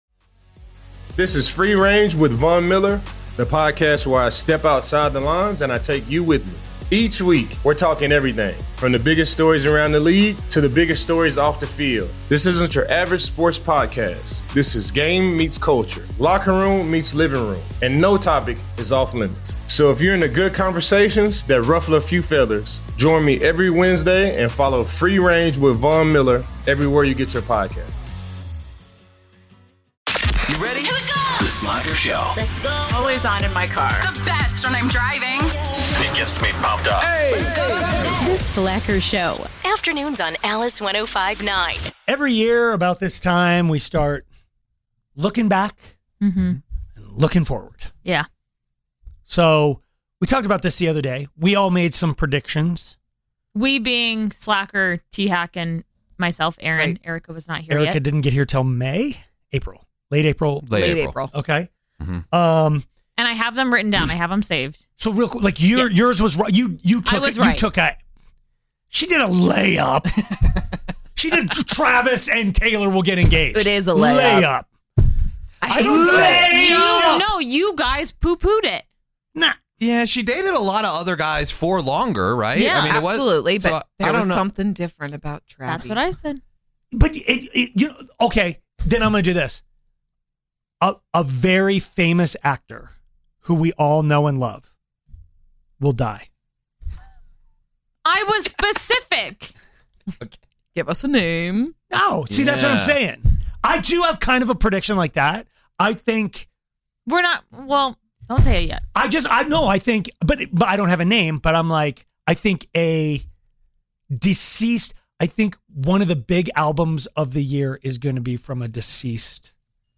1 FULL SHOW: Inside Radio Article; A new era for NYC soon. 1:07:31 Play Pause 15h ago 1:07:31 Play Pause Play later Play later Lists Like Liked 1:07:31 Curtis Sliwa fills in for Mark Simone. He discusses an Inside Radio article about him highlighting his strong engagement with Millennials and Gen Z. Curtis breaks down how his unique approach resonates with younger audiences, encouraging listeners to call in and share their thoughts on why they connect with him.